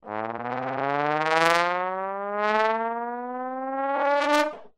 Trombone-Ringtone im mp3-Format.
trombone.mp3